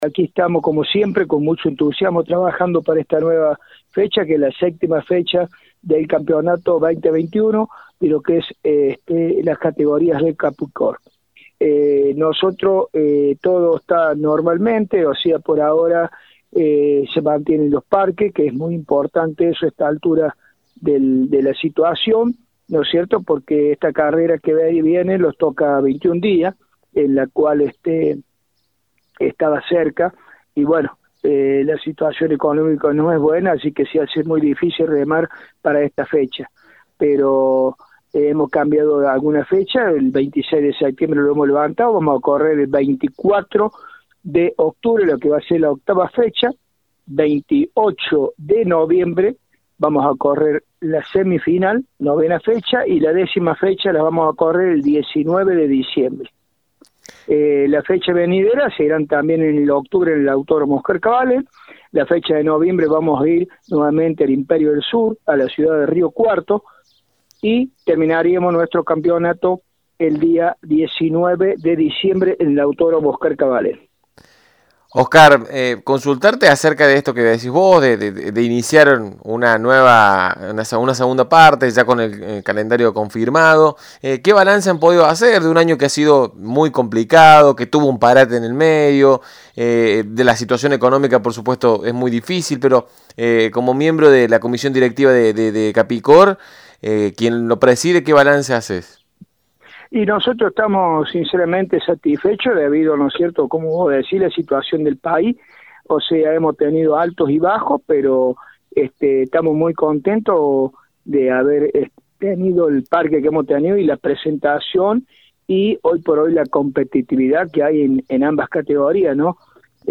En la previa de esta competencia que se avecina, en Poleman Radio estuvimos dialogando